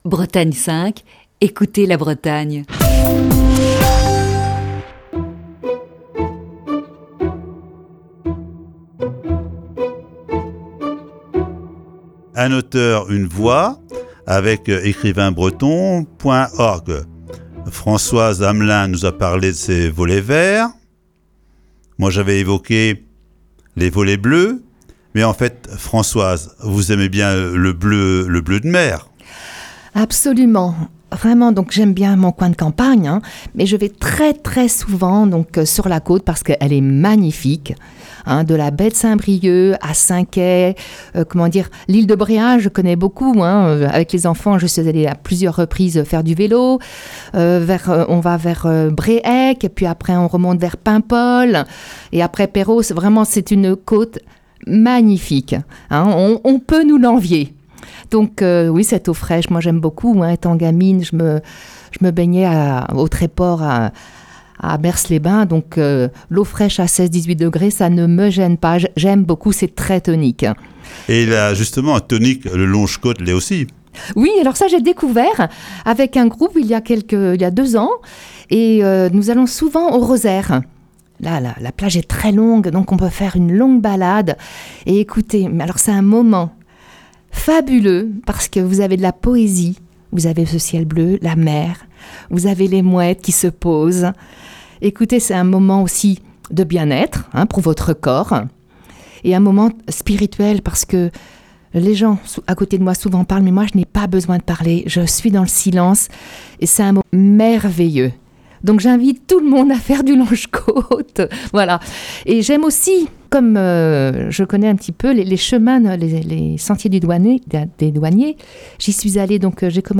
Voici ce mercredi, la troisième partie de cet entretien.